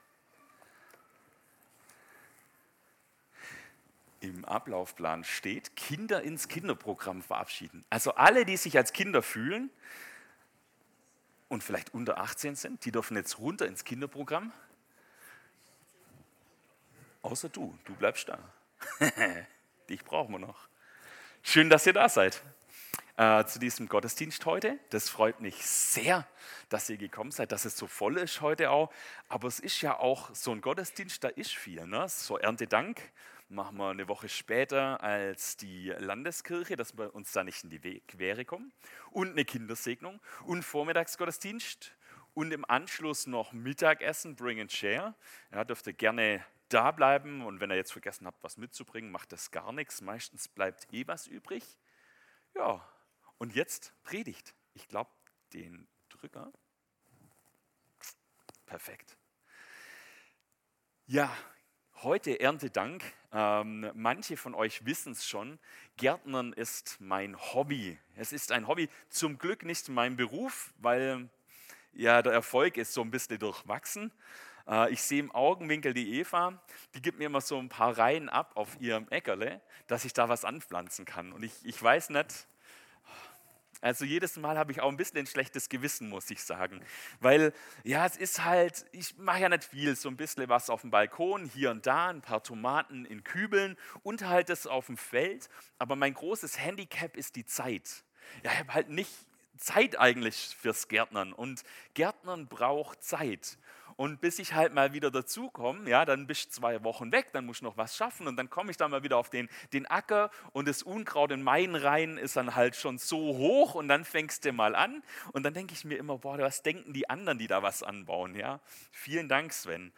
Predigt am 12.10.2025